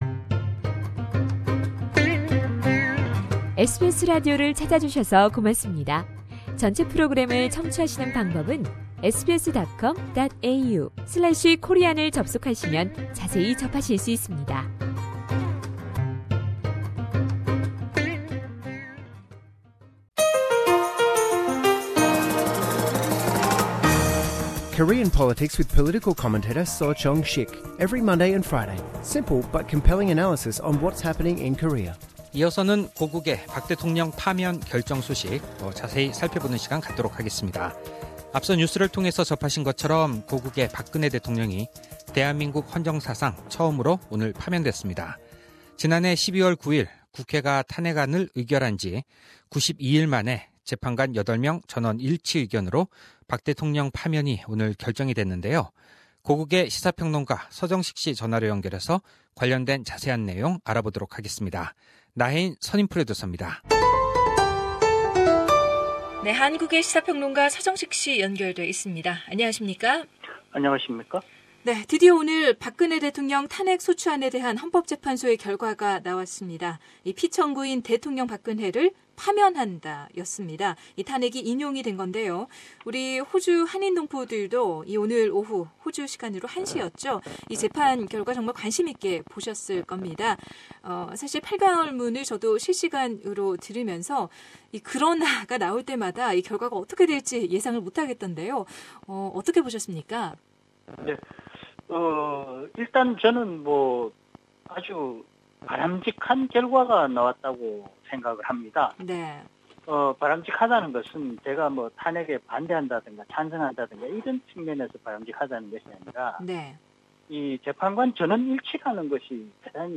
시사 평론가